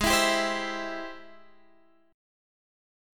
Ab+M7 chord